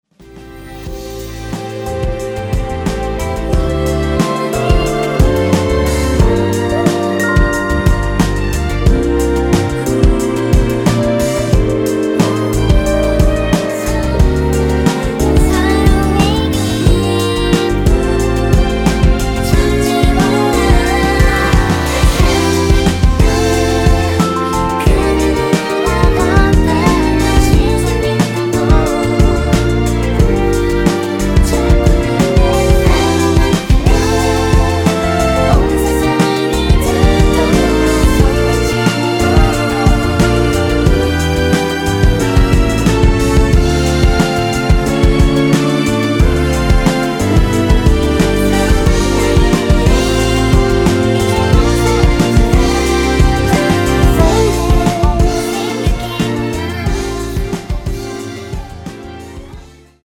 원키에서(+2)올린 코러스 포함된 MR입니다.
앞부분30초, 뒷부분30초씩 편집해서 올려 드리고 있습니다.
중간에 음이 끈어지고 다시 나오는 이유는